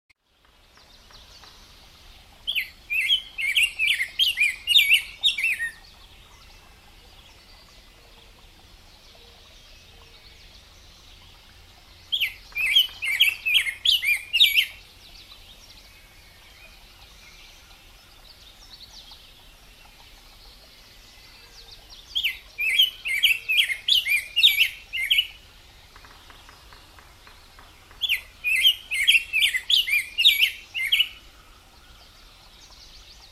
Bird song mp3s files are in the public domain.
rose-breasted-grosbeak.mp3